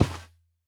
Minecraft Version Minecraft Version 1.21.4 Latest Release | Latest Snapshot 1.21.4 / assets / minecraft / sounds / mob / camel / step_sand4.ogg Compare With Compare With Latest Release | Latest Snapshot
step_sand4.ogg